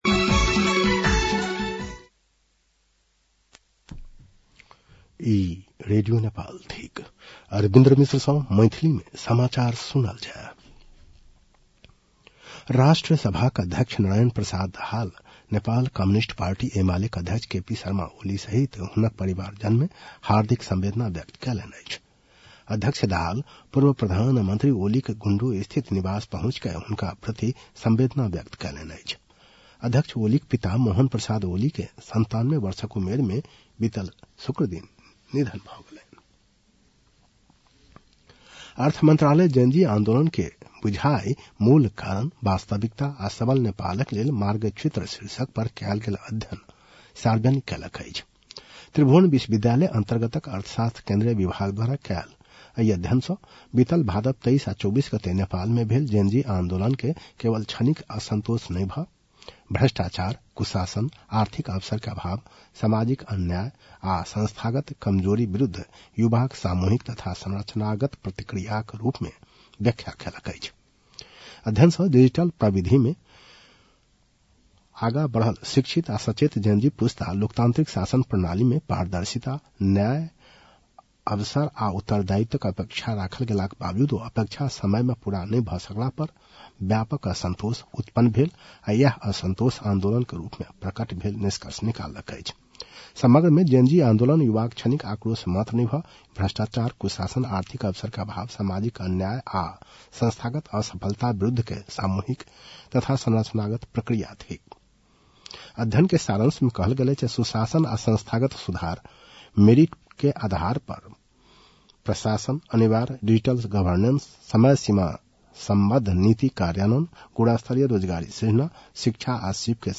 मैथिली भाषामा समाचार : १ चैत , २०८२
Maithali-news-12-01.mp3